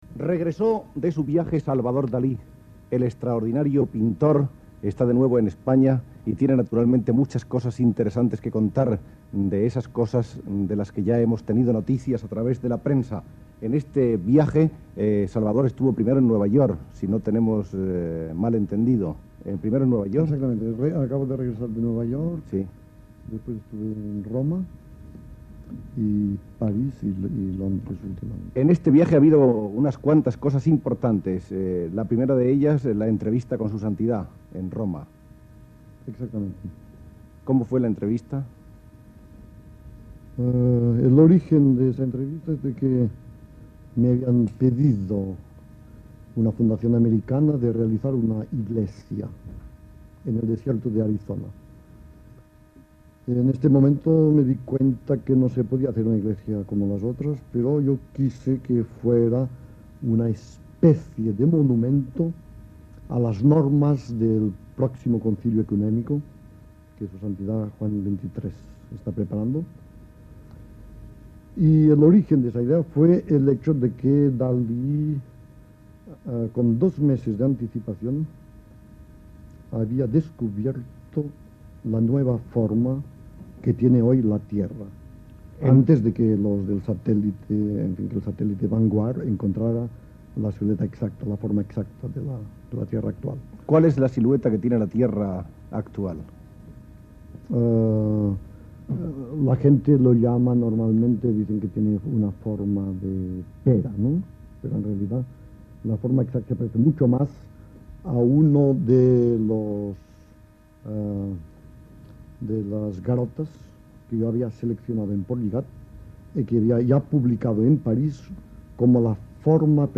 Entrevista al pintor Salvador Dalí sobre el seu viatge a Nova York, a Roma i la seva visita al Sant Pare Joan XXIII, a París.